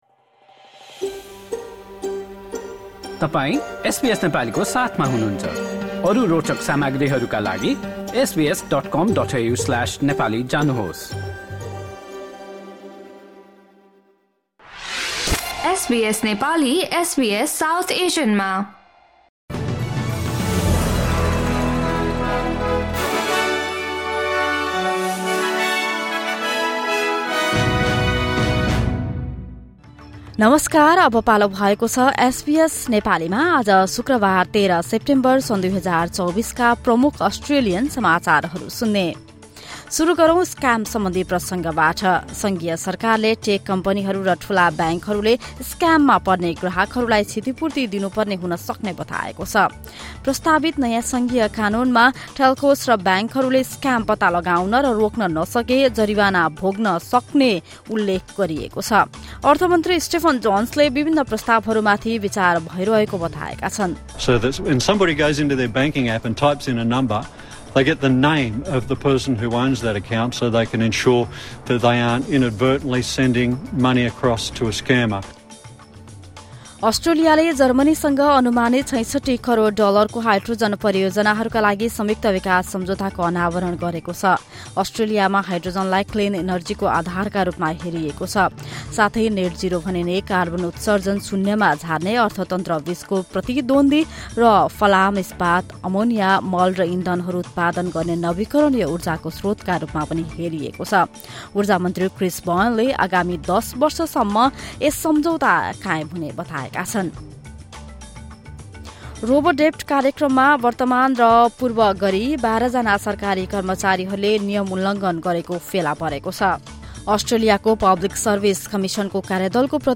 SBS Nepali Australian News Headlines: Friday, 13 September 2024